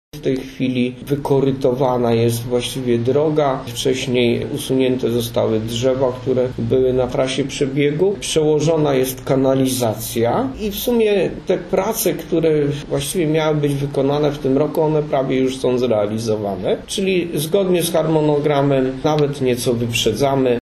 Niemal wszystkie prace zaplanowane na ten rok w ramach budowy łącznika do powstającej obwodnicy Niska zostały już zrealizowane. Poinformował o tym burmistrz Niska Waldemar Ślusarczyk: